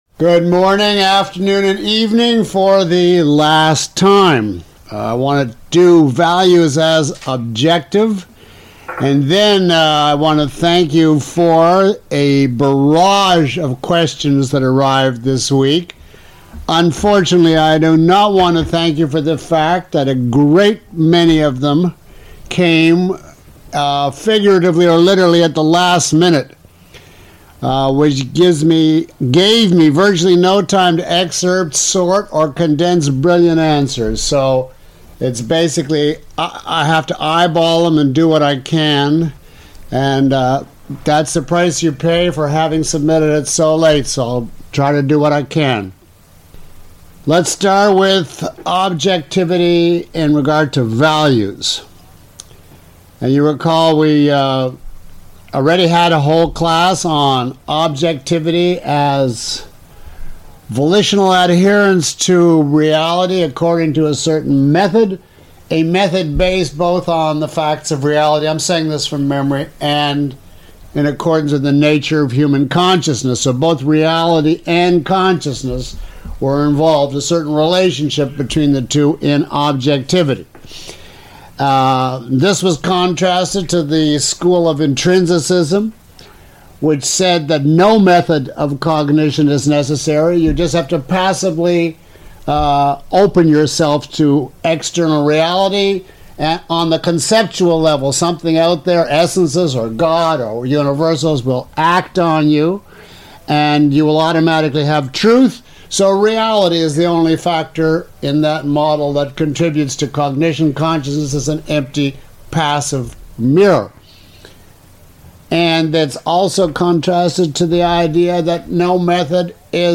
[Lecture Ten] Objectivism Through Induction